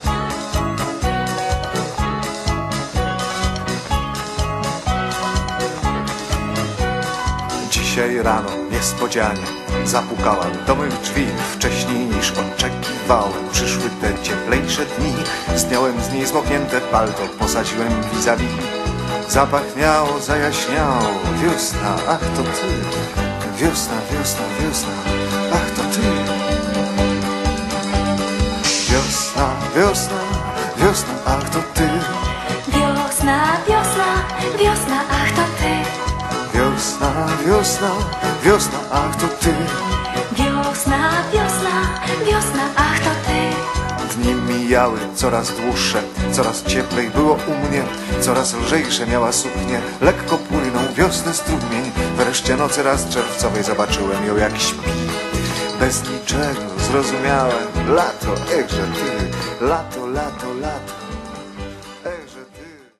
VOC GUITAR KEYB BASS DRUMS TEKST